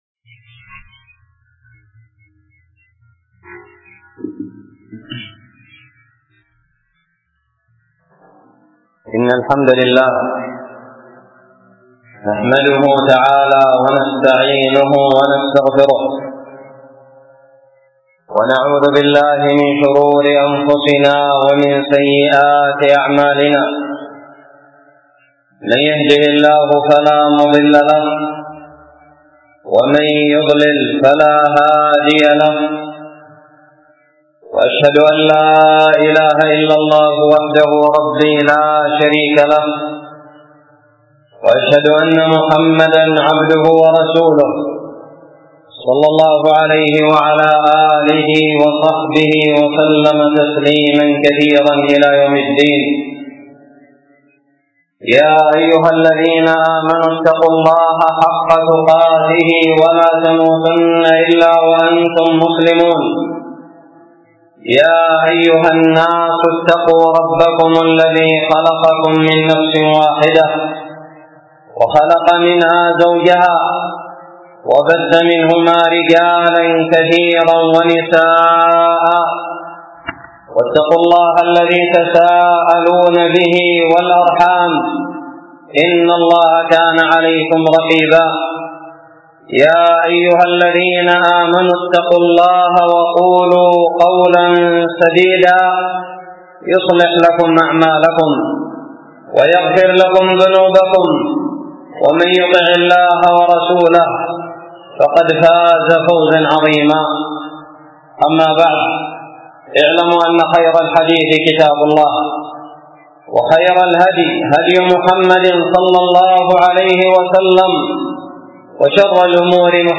خطب الجمعة
ألقيت بدار الحديث السلفية للعلوم الشرعية بالضالع في 3 رجب 1437هــ